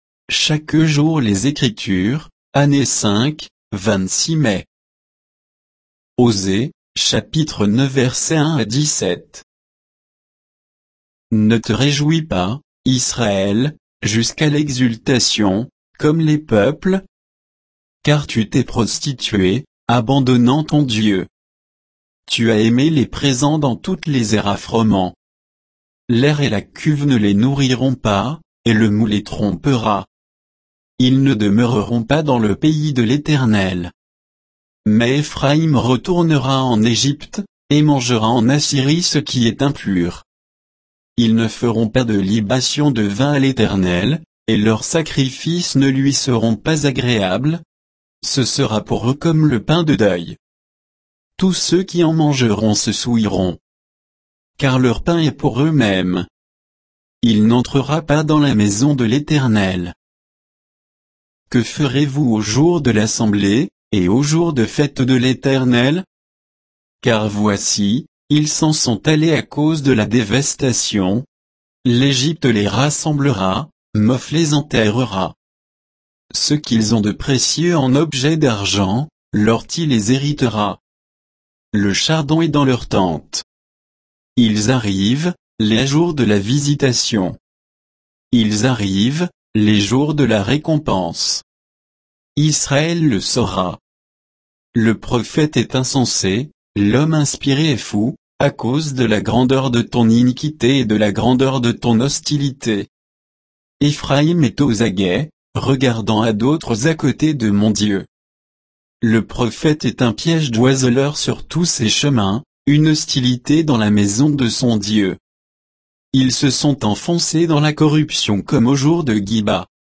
Méditation quoditienne de Chaque jour les Écritures sur Osée 9, 1 à 17